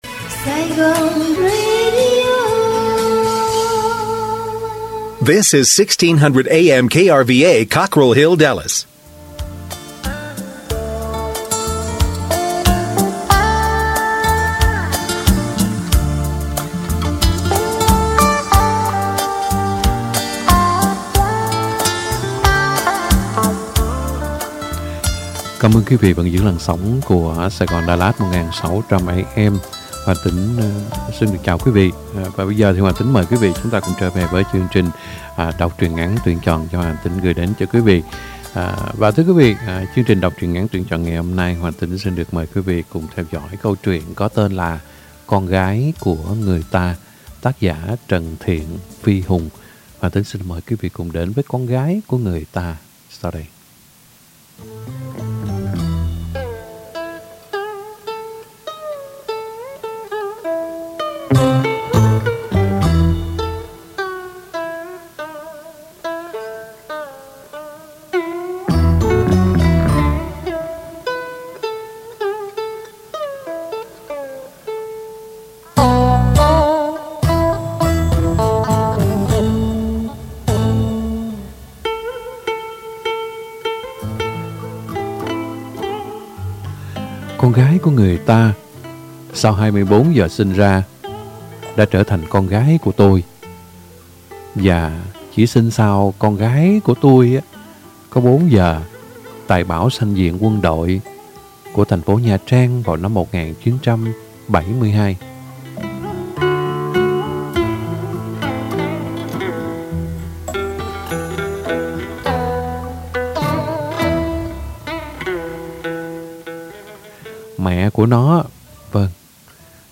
Đọc truyện ngắn - Con Gái Của Người Ta !